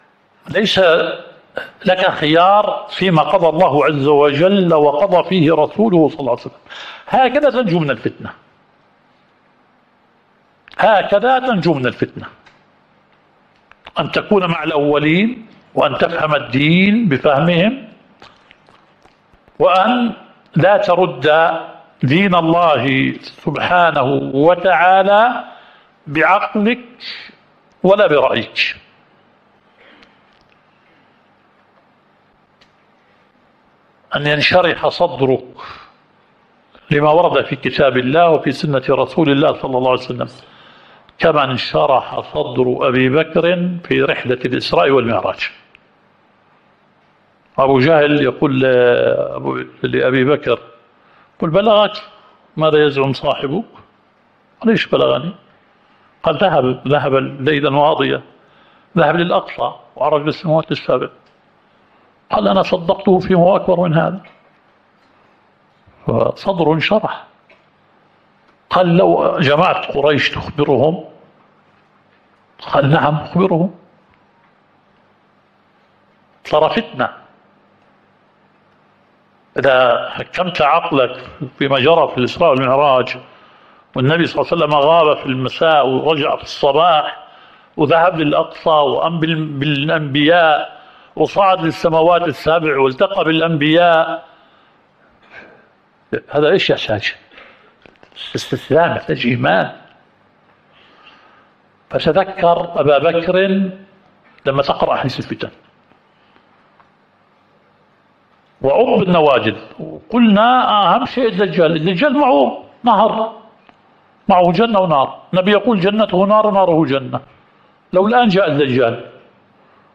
الدورة الشرعية الثالثة للدعاة في اندونيسيا – منهج السلف في التعامل مع الفتن – المحاضرة الرابعة.